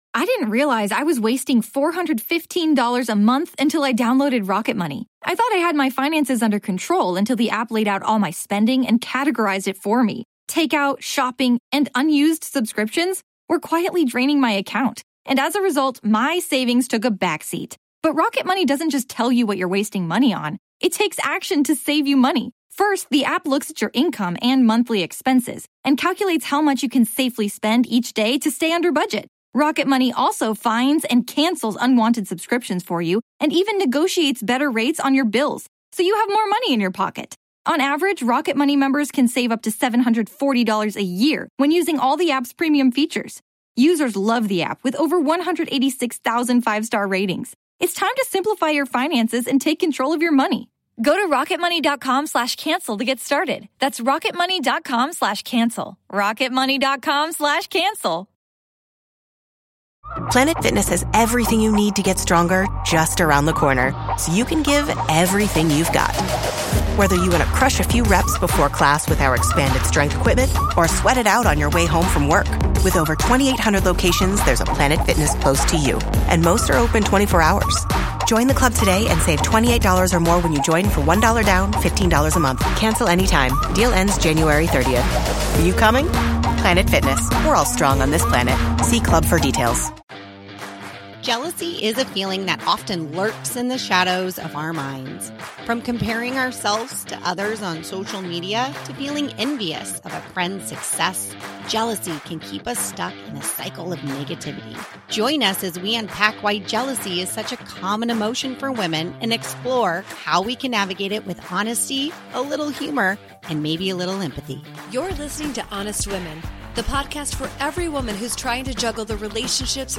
You don't want to miss this honest conversation.